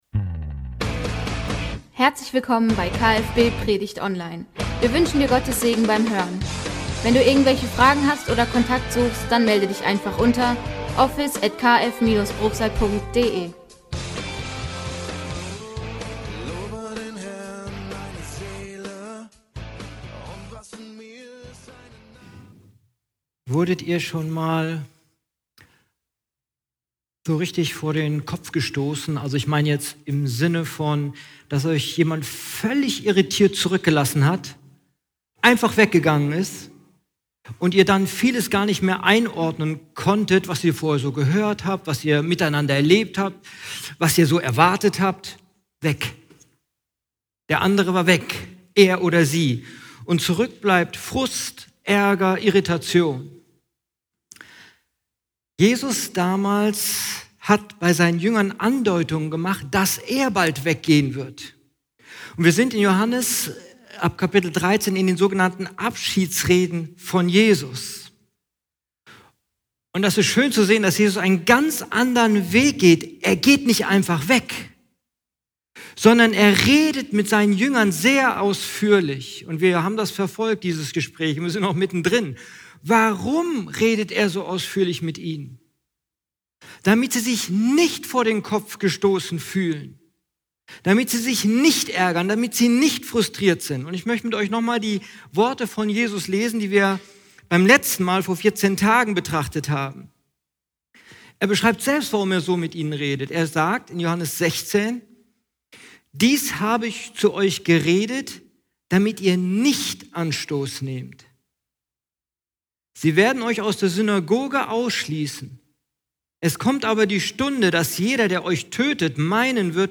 Gottesdienst: Woher nehmen?